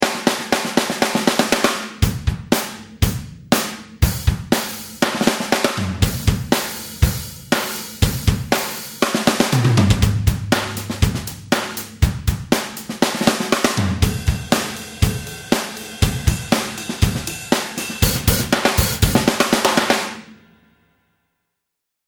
| pop/rock drum sequence |